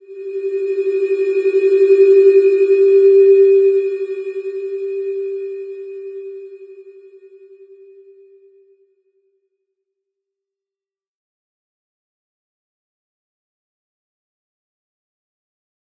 Slow-Distant-Chime-G4-f.wav